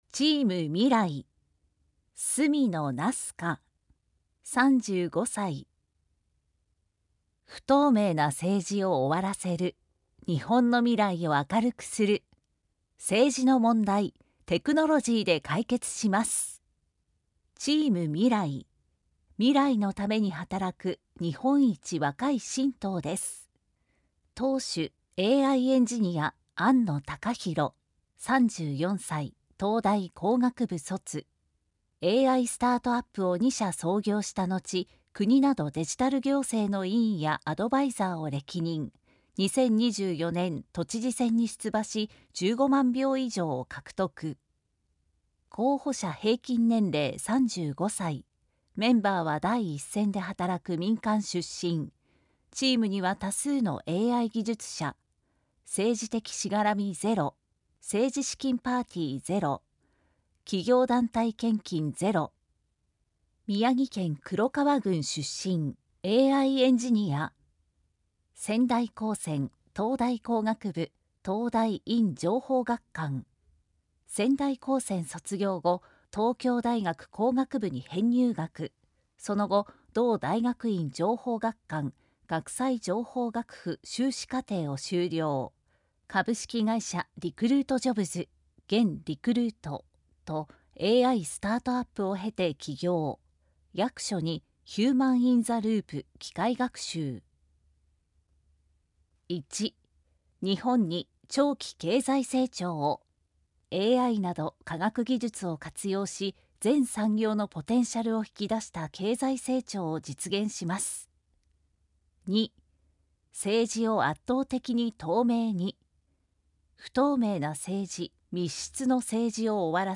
参議院議員通常選挙候補者・名簿届出政党等情報（選挙公報）（音声読み上げ用）（音声版）